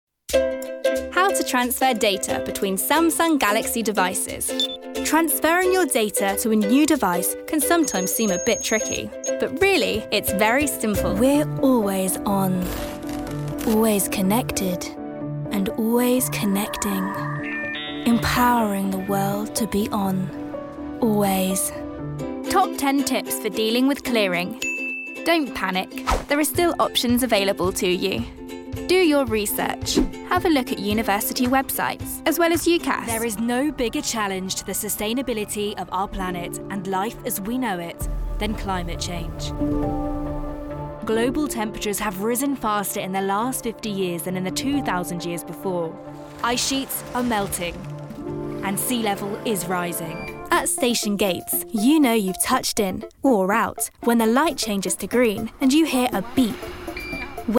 Comercial, Joven, Cool, Versátil, Amable
Corporativo